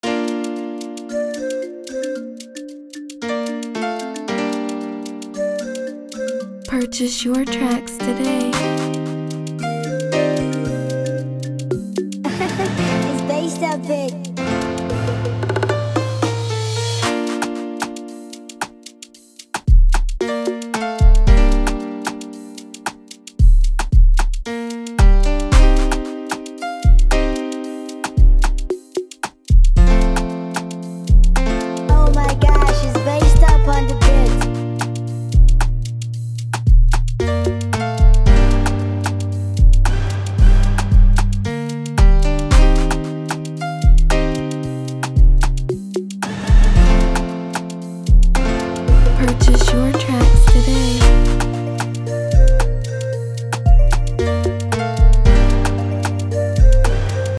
an enchanting Afrobeats instrumental
this freestyle-type beat sets the perfect tone at 100 BPM.